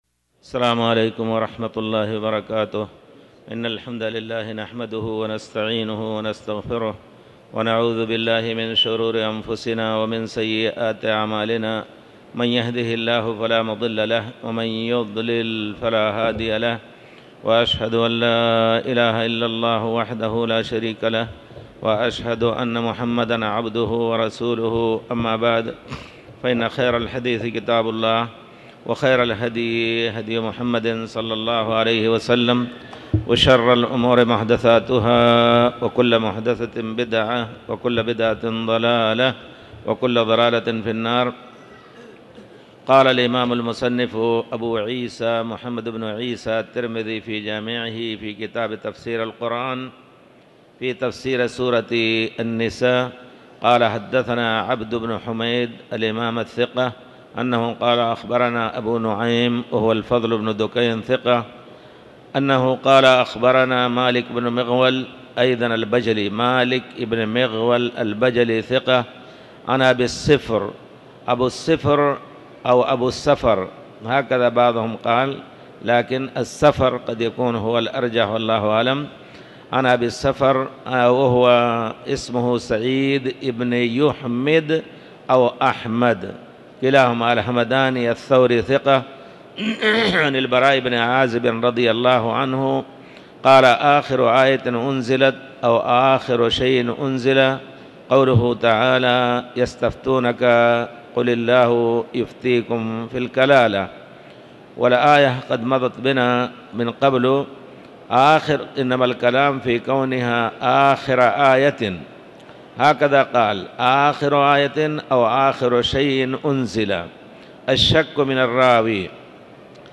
تاريخ النشر ٢٦ محرم ١٤٤٠ هـ المكان: المسجد الحرام الشيخ